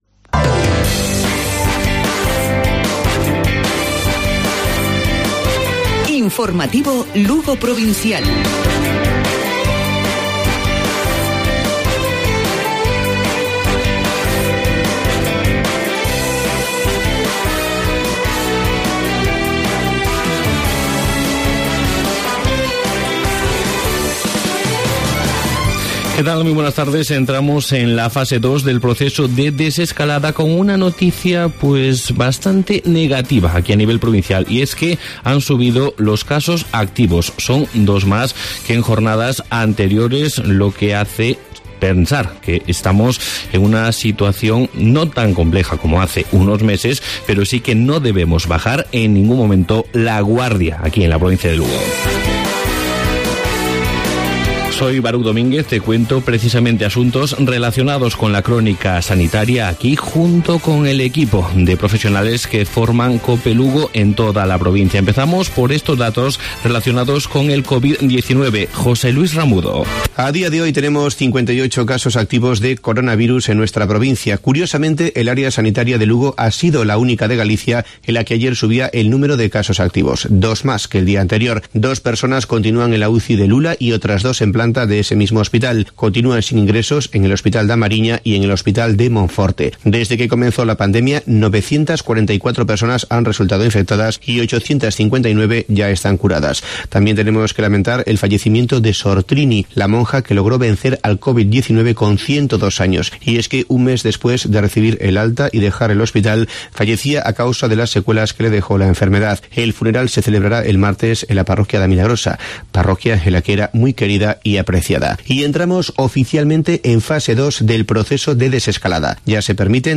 Informativo Provincial Cope.